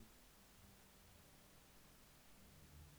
Записывал на встроенную звковуху Intel® High Definition Audio в микрофонный вход (line-in разъема нету) без усиления микрофона в настройках драйвера. На первый взгляд (точнее слух) никаких шумов нету, но стоит подключить какой-нибудь плагин (хотя бы стандартный кубэйсовский AmpSimulator) - появляется ровный шум. Прикрепил ничем необработанный сэмпл этого шума: (������ �� ��������) Я понимаю, что звуковуха непригодна для игры/записи через комп, но хотелось бы мнения знающих людей: откуда берется такой шум?